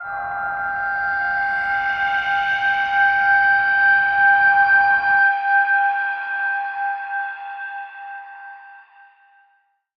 G_Crystal-G7-mf.wav